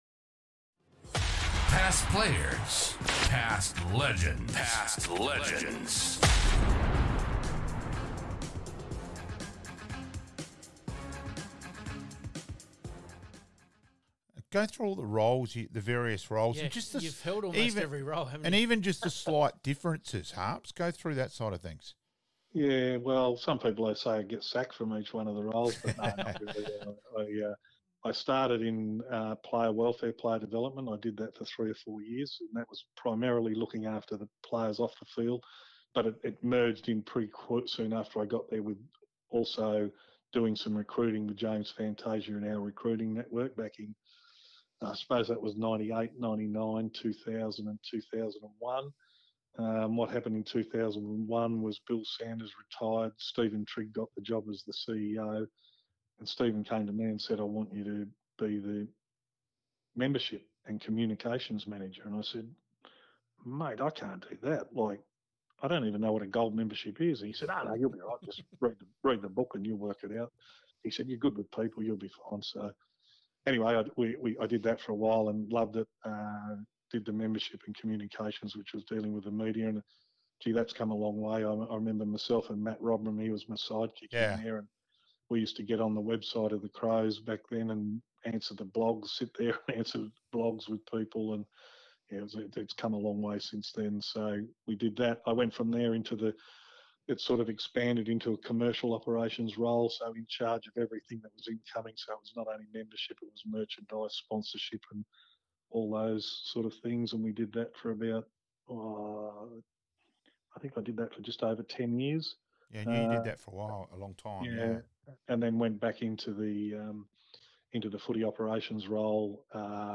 Past Players Past Legends - Interview (only) with some of our special guests